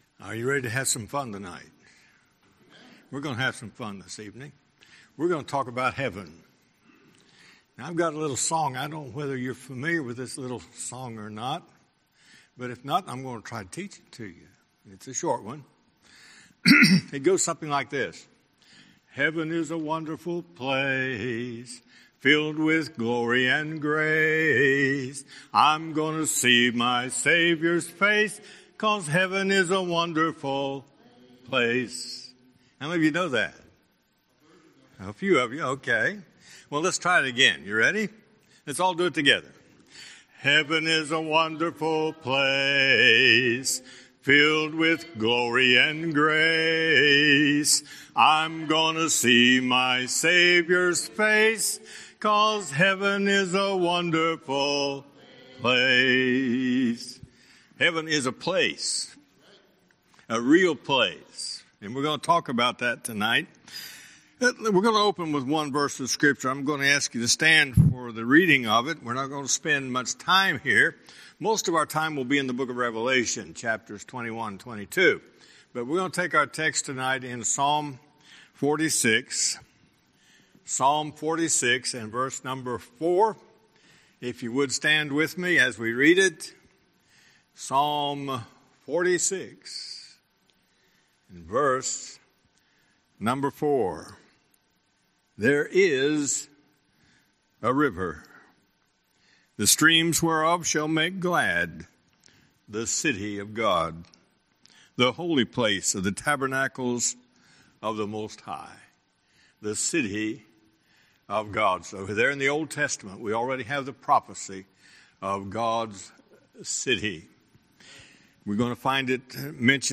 Sermons
Guest Speaker